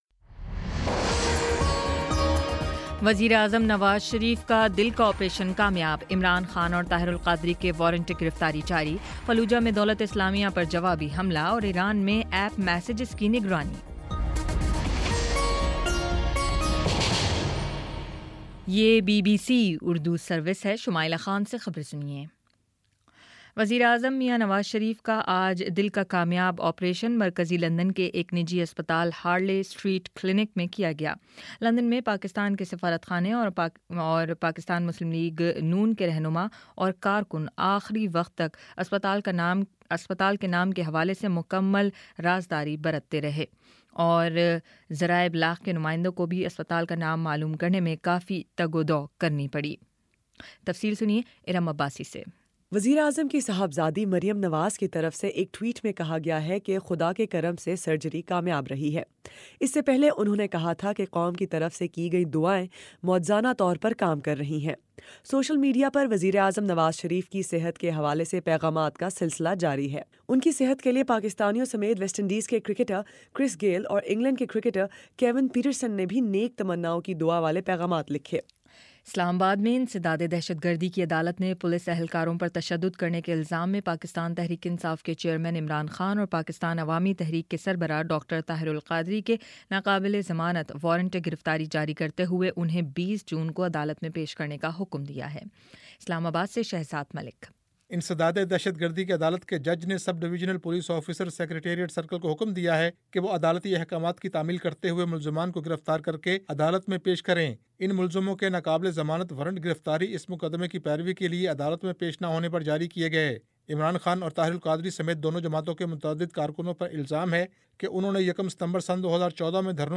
مئی 31: شام سات بجے کا نیوز بُلیٹن